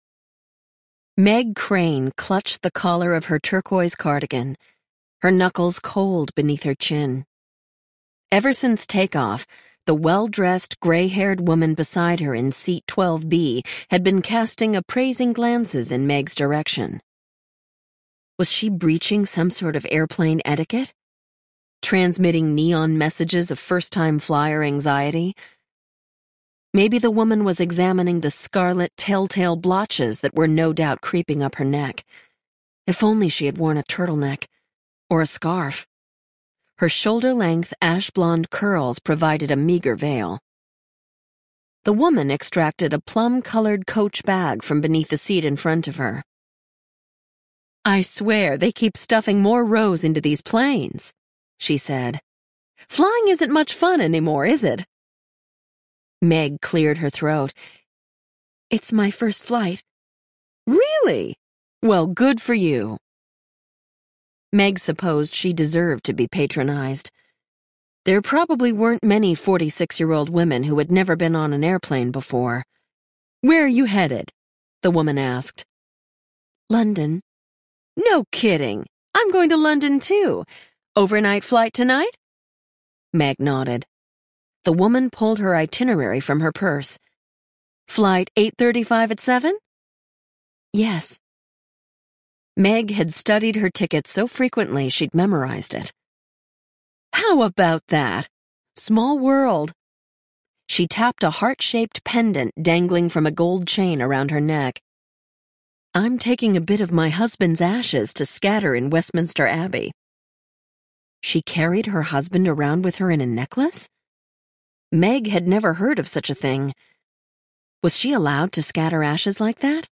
Two Steps Forward Audiobook
11.5 Hrs. – Unabridged